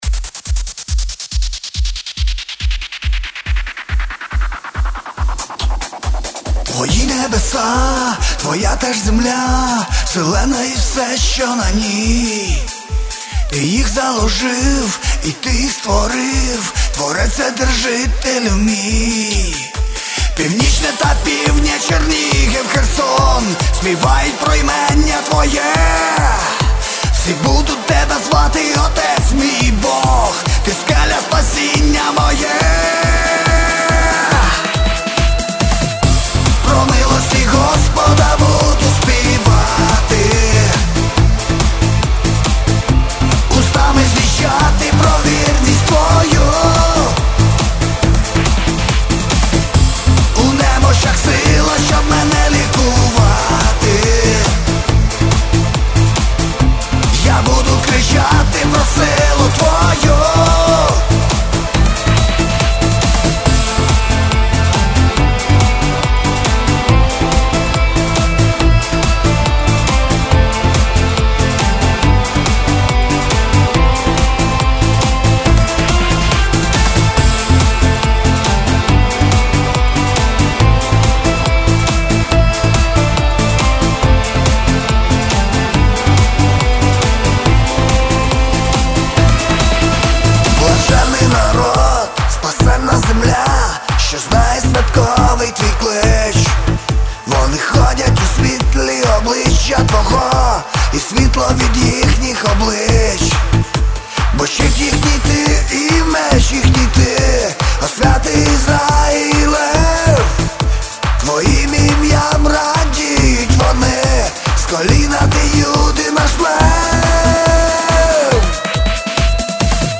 121 просмотр 169 прослушиваний 2 скачивания BPM: 140